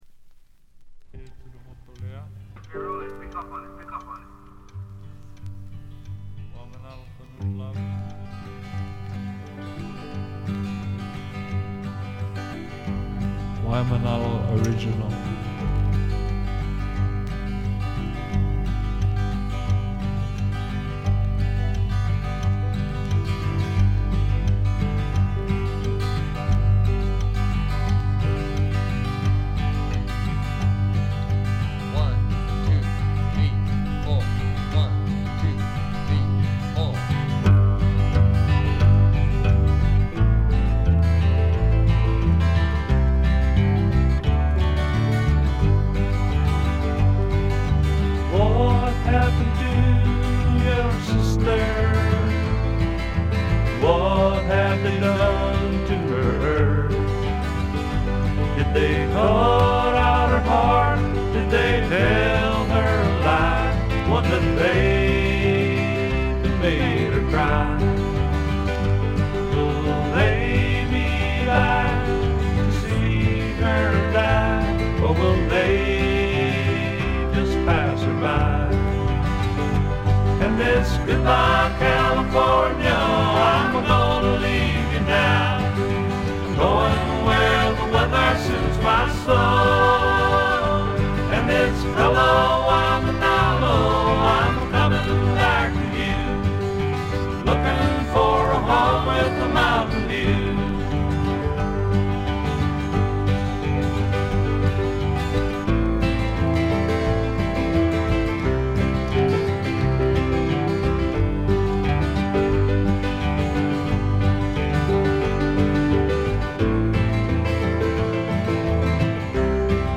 部分試聴ですがわずかなノイズ感のみ。
潮風に乗せたちょっとフォーキーなAORといったおもむきが心地よいです。
試聴曲は現品からの取り込み音源です。